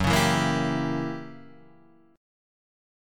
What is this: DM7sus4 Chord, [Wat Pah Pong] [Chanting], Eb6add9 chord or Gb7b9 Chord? Gb7b9 Chord